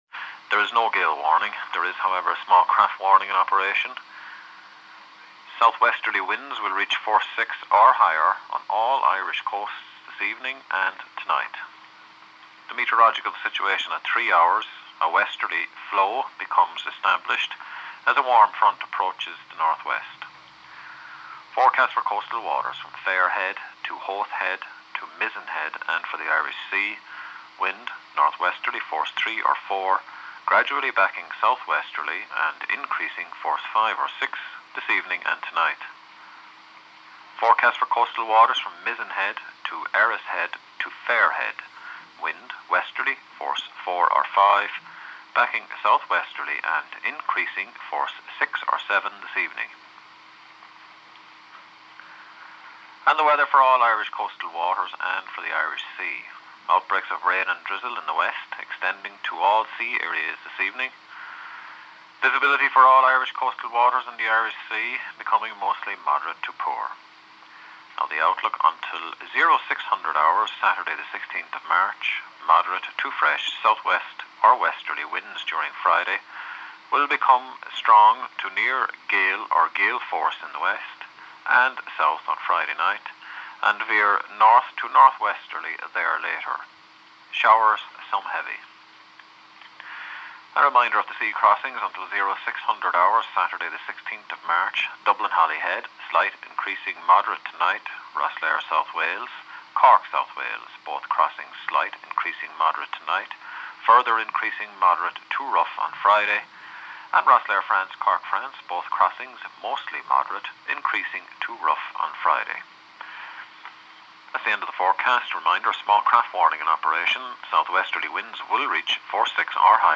Sea area forecast VHF 14 March